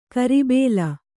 ♪ karibēla